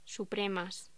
Locución: Supremas
voz